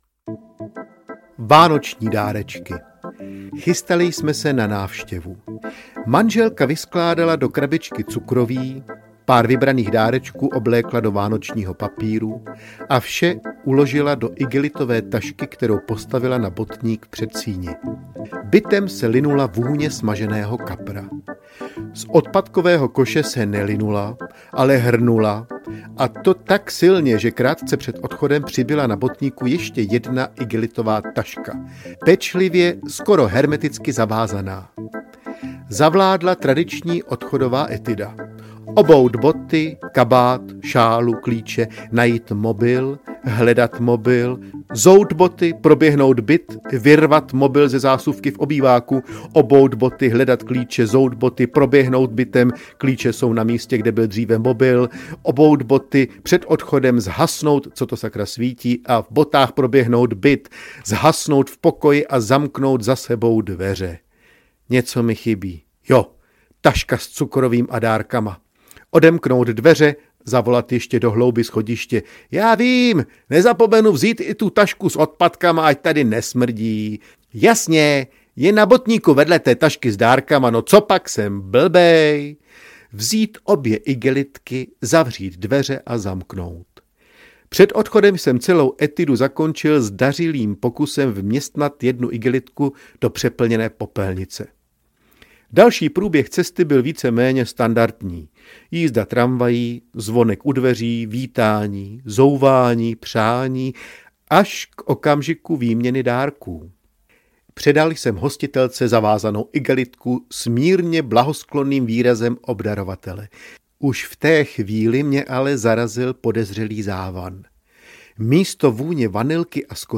Rozhovor s Ufonem audiokniha
Ukázka z knihy